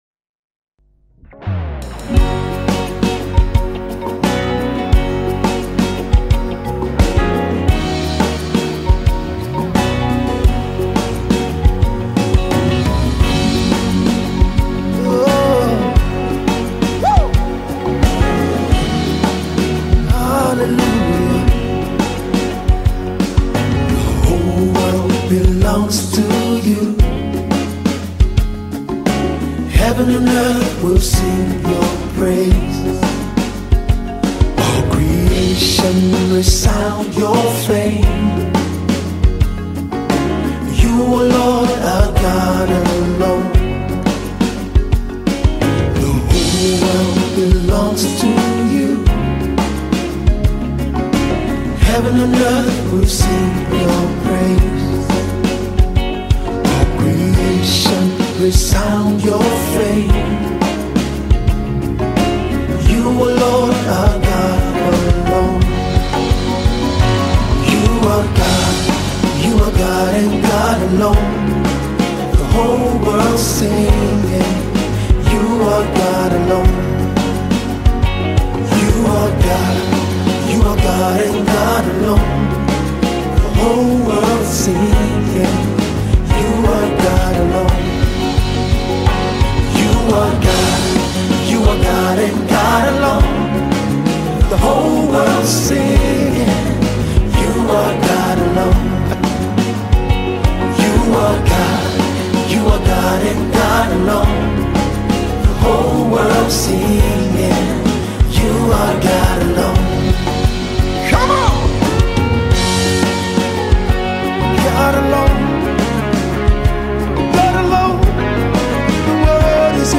Nigerian Yoruba Fuji track